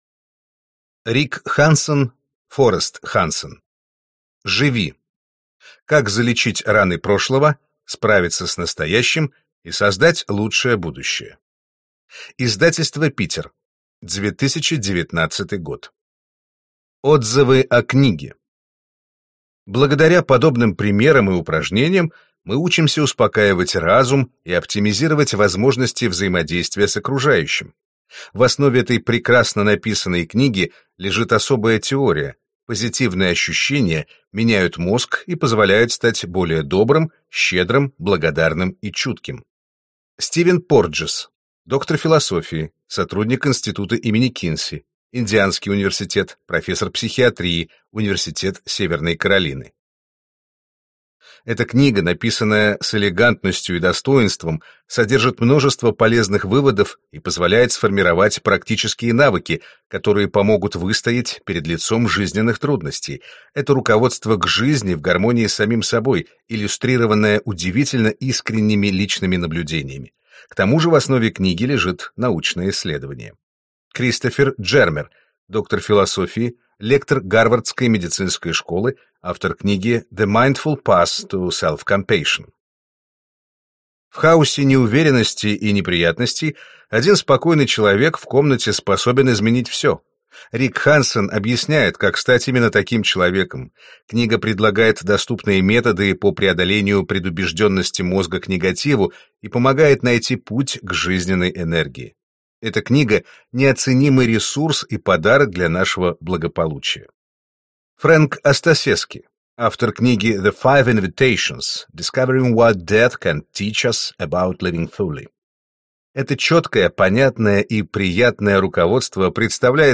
Аудиокнига Живи.